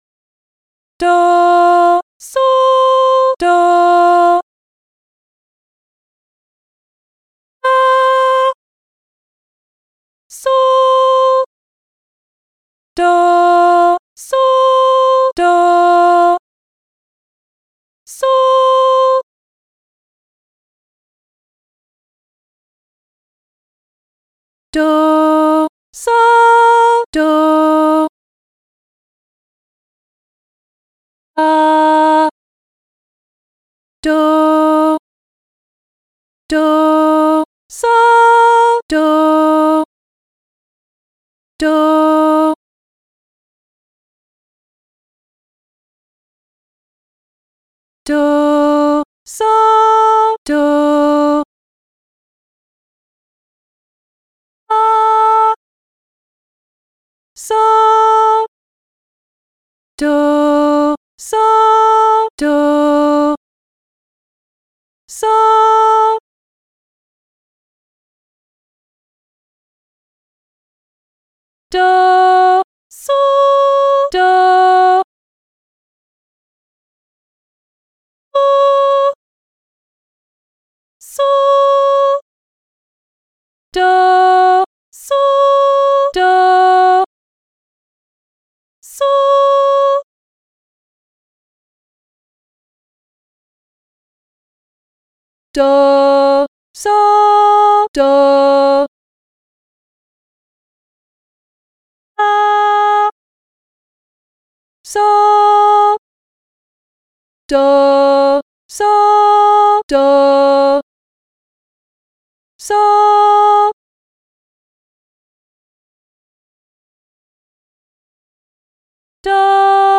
Female Voice
Easy Training Exercise: Do, So (members can download these audio tracks for more practice)